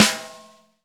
Index of /90_sSampleCDs/Roland L-CDX-01/SNR_Snares 7/SNR_Sn Modules 7
SNR JZ SN1.wav